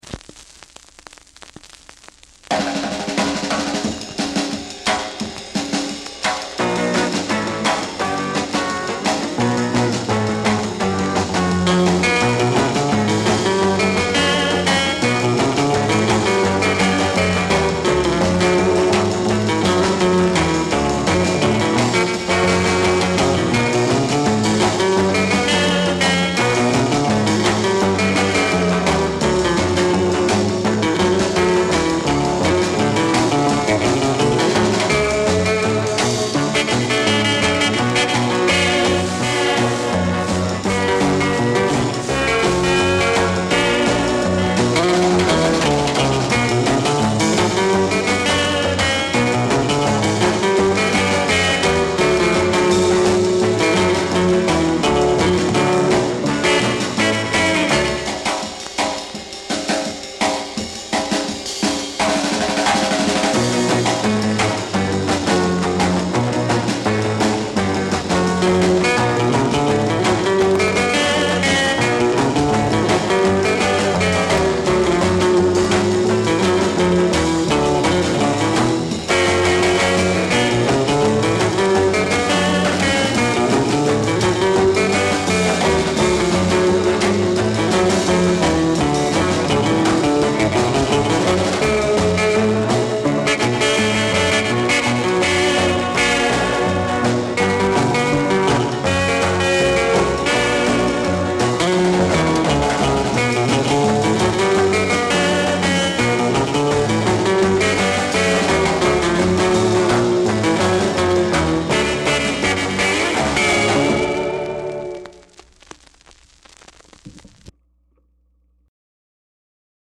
instrumental surf rock band
rhythm guitar
drums